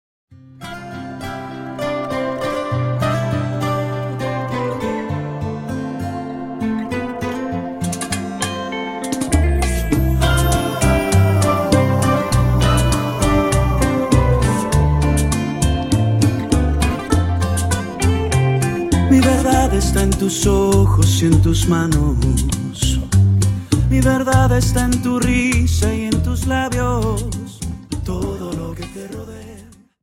Dance: Rumba 25